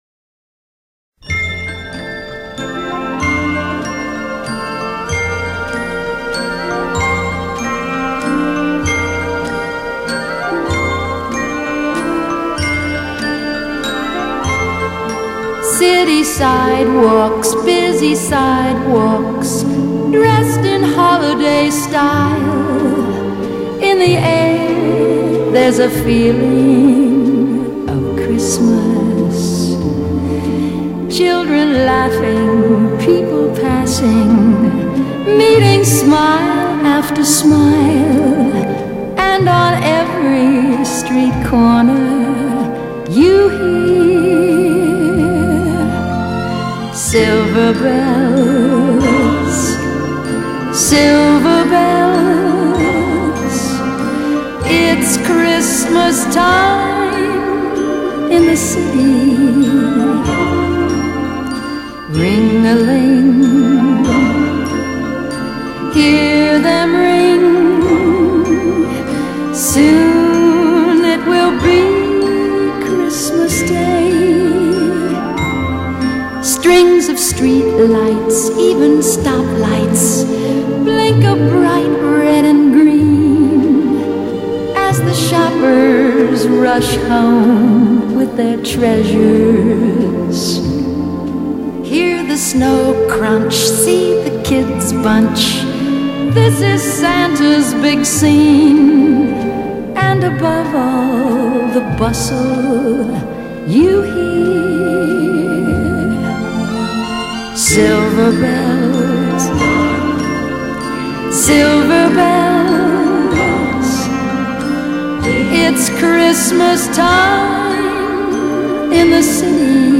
类　　别: Pop, Jazz, Christmas　　　　  .